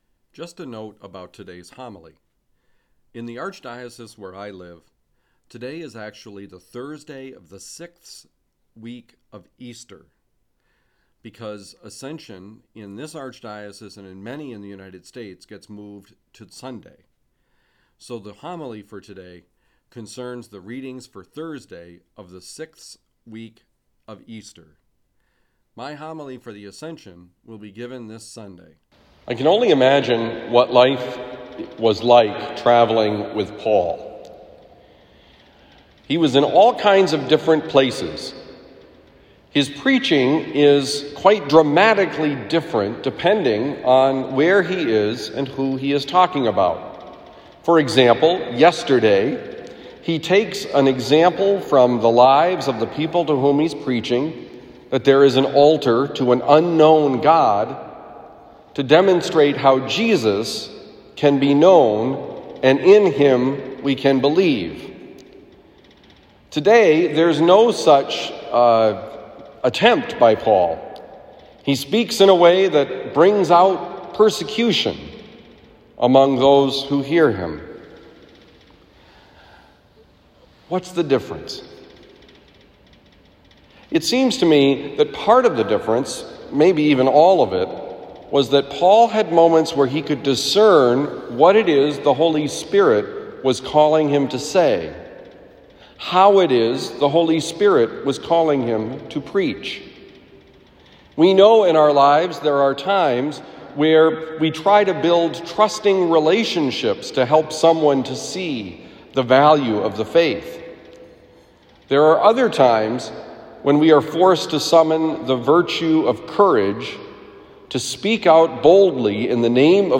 Homily for Thursday, May 13, 2021
Given at Christian Brothers College High School, Town and Country, Missouri.